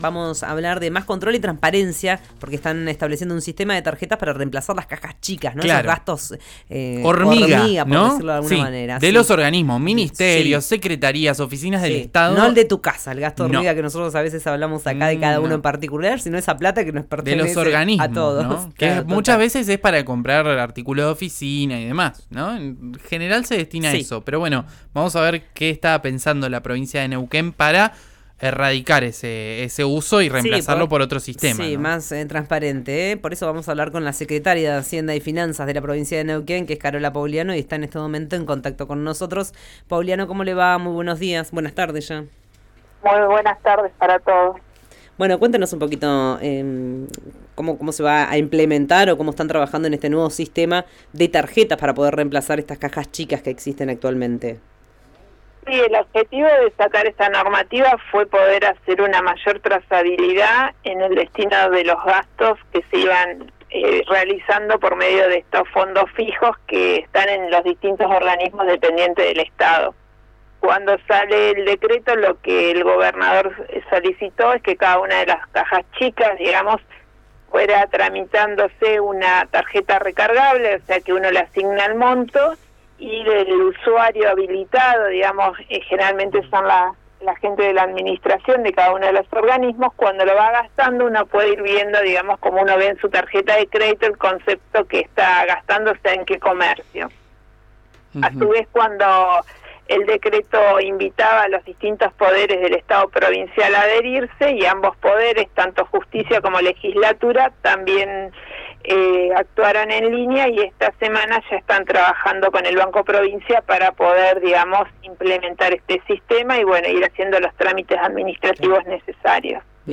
Escuchá a Carola Pogliano, Secretaria de Hacienda y Finanzas de la Provincia de Neuquén en RÍO NEGRO RADIO: